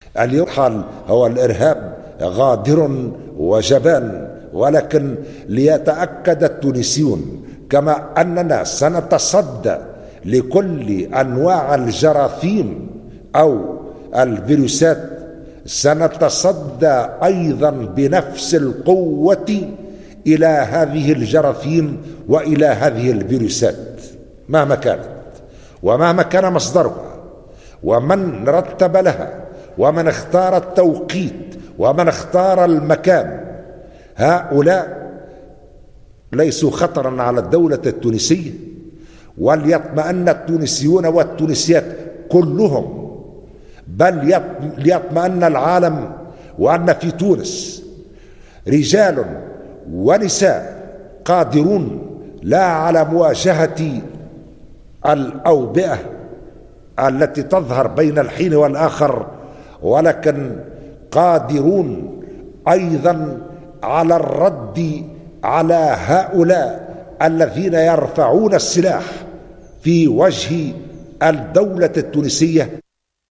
توجّه رئيس الجمهورية قيس سعيّد خلال اجتماع مجلس الأمن القومي مساء اليوم برسالة طمأنة للتونسيين اثر الاعلان عن ارتفاع عدد الاصابات المؤكدة بفيروس كورونا المستجد.